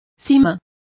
Complete with pronunciation of the translation of chasms.